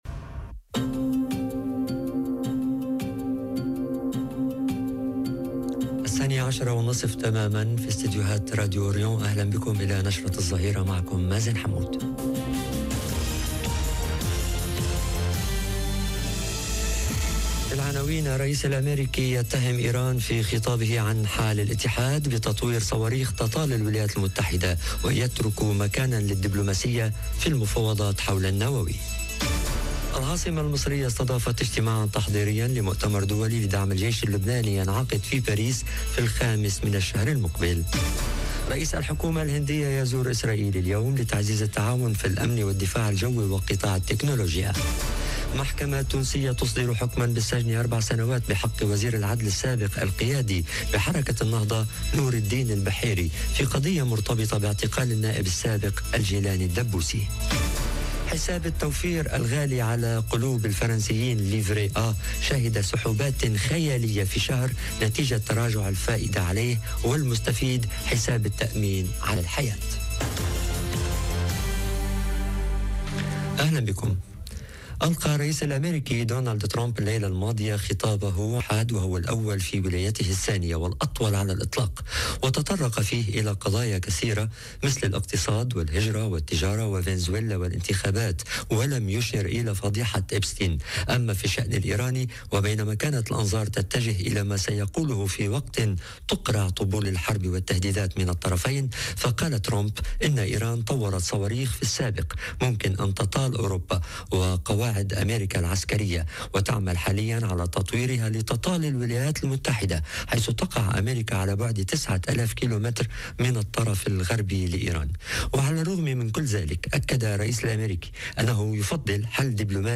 نشرة الظهيرة.. صواريخ إيرانية تطال أمريكا ورئيس حكومة الهند بإسرائيل